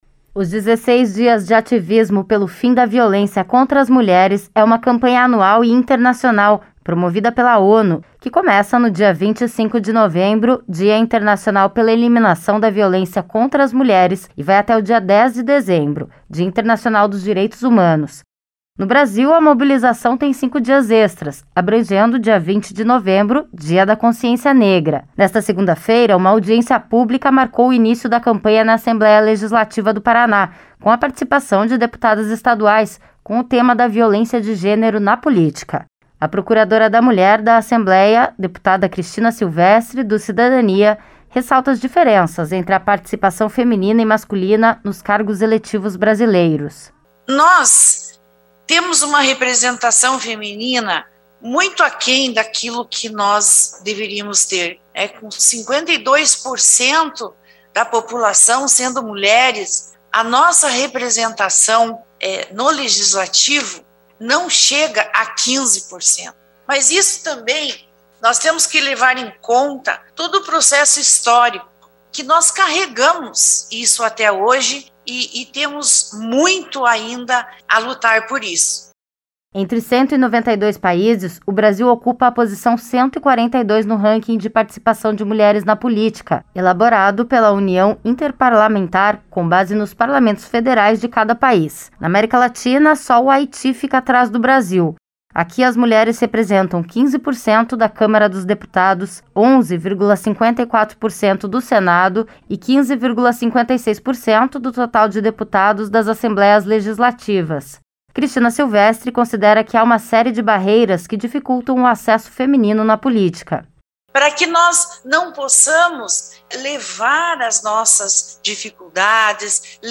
A procuradora da Mulher da Assembleia Legislativa, deputada Cristina Silvestri (CDN), ressalta as diferenças entre a participação feminina e masculina nos cargos eletivos brasileiros.